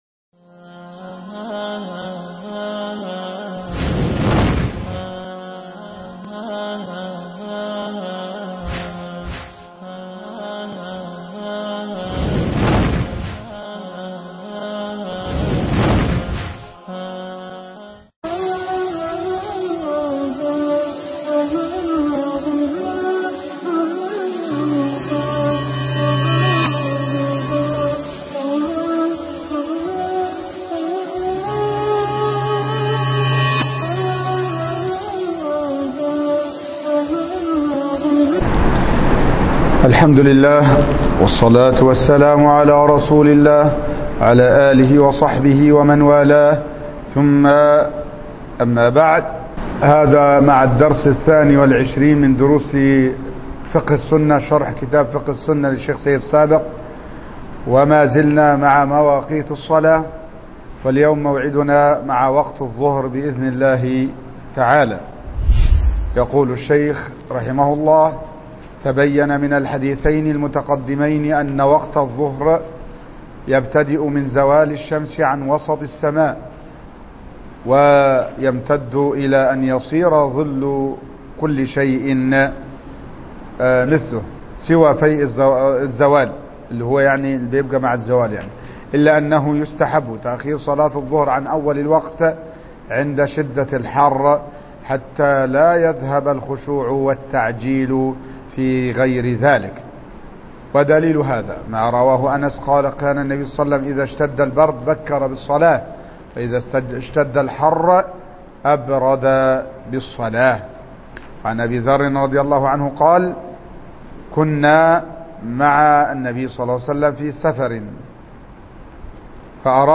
شرح كتاب فقه السنة الدرس 22